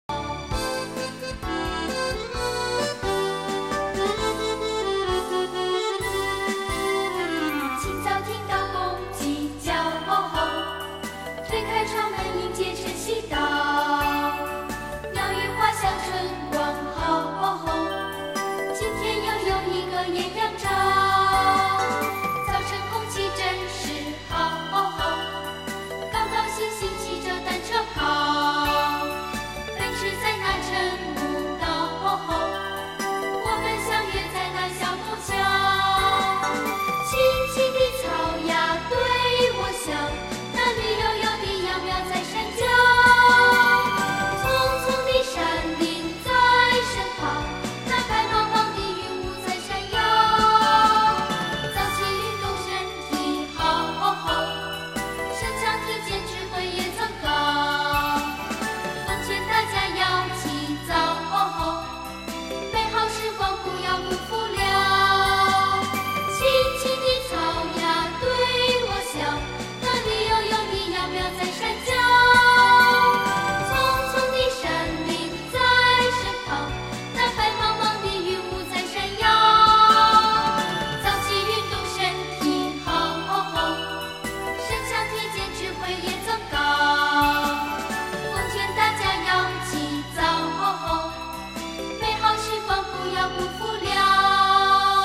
低音质试听： (WMA/128K)